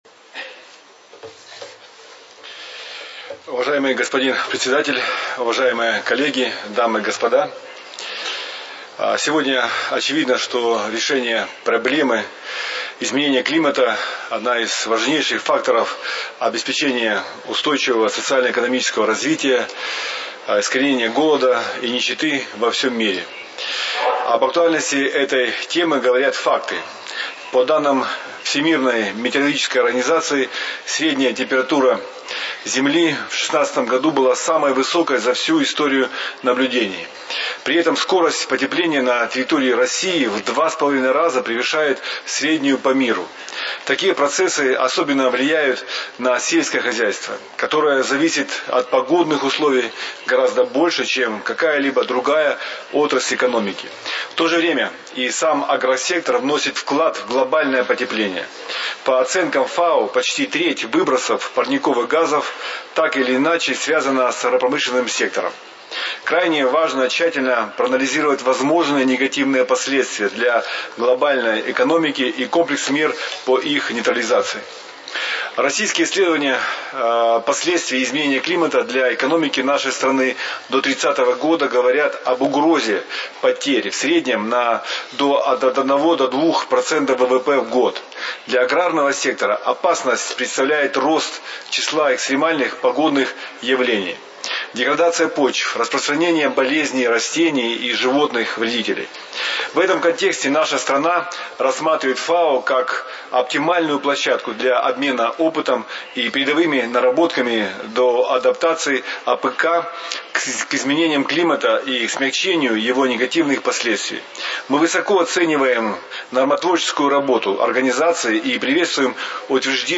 FAO Conference
Statements by Heads of Delegations under Item 9:
His Excellency Alexander N. Tkachev, Minister for Agriculture of the Russian Federation